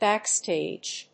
音節báck・stàge 発音記号・読み方
/ˈbæˈkstedʒ(米国英語), ˈbæˈksteɪdʒ(英国英語)/